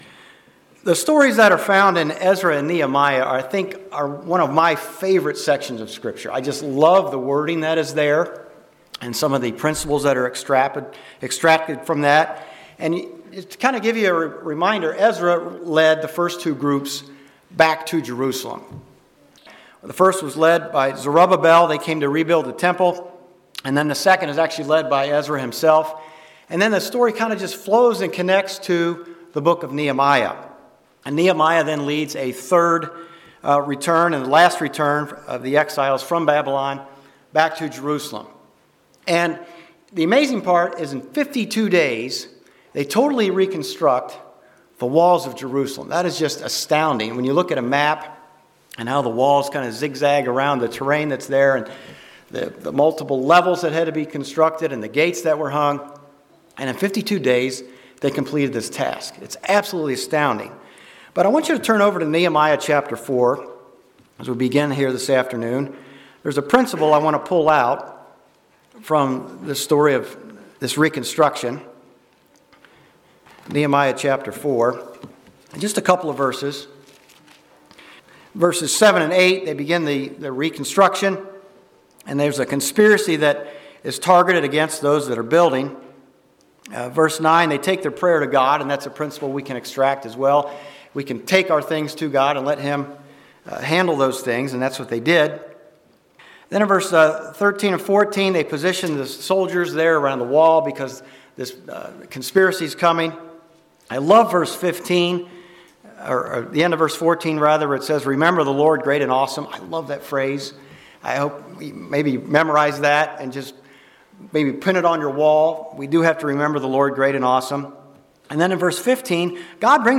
In order to fight for the truth, we must know the truth. This sermon covers the doctrine of baptism.